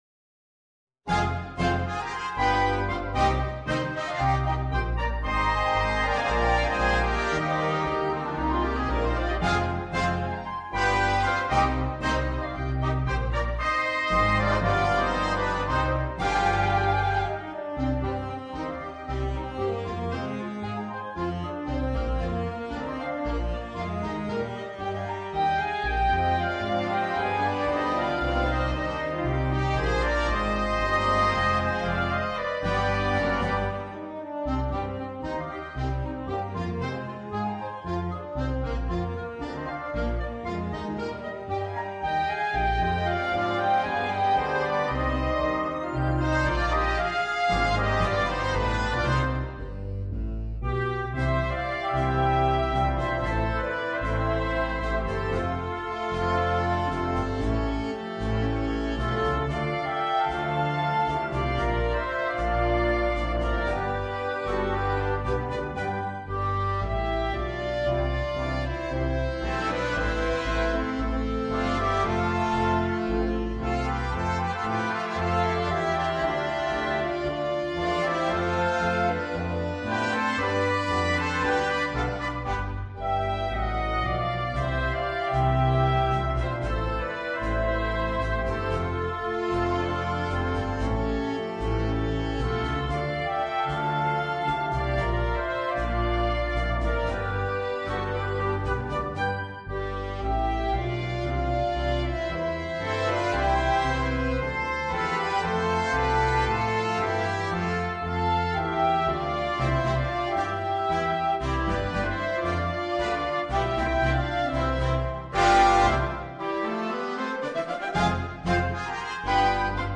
Un tango originale per banda di grande effetto.
tango